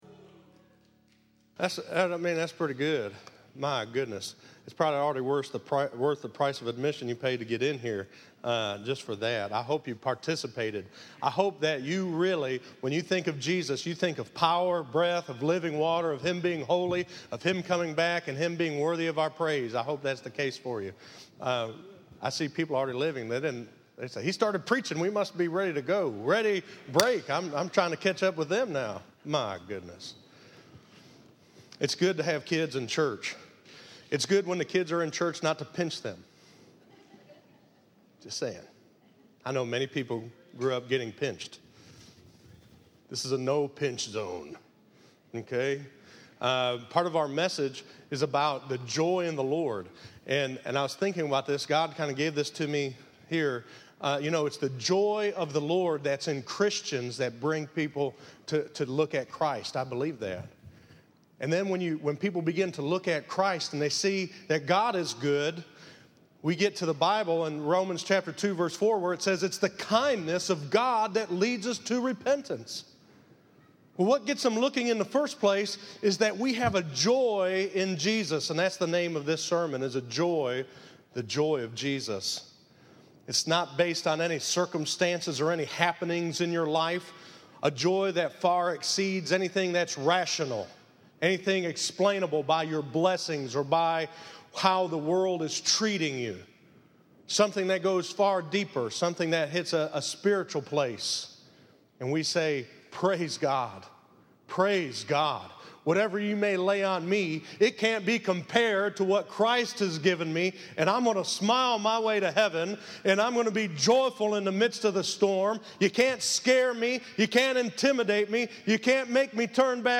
Listen to The Joy of Jesus - 09_20_15_sermon.mp3